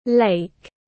Lake /leɪk/